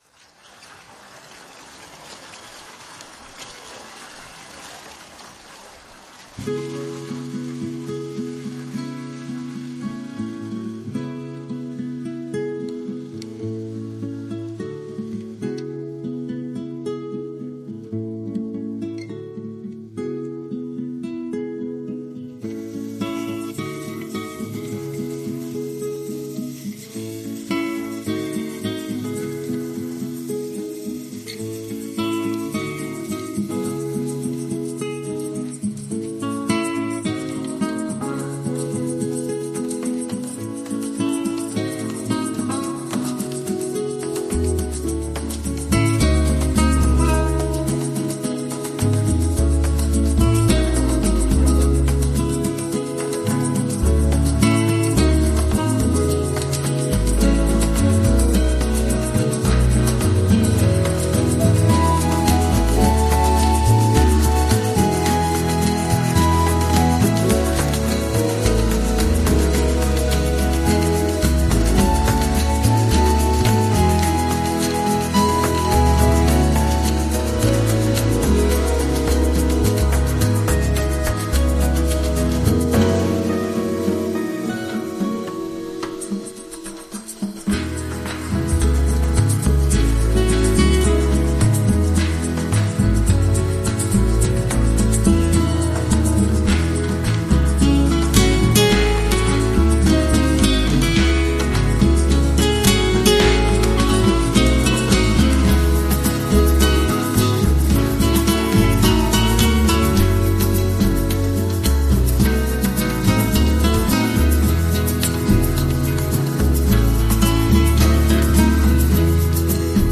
・メインセクションは、アコースティックギターとピアノが中心となり、リズミカルなボサノバのビートが曲の進行を支えます。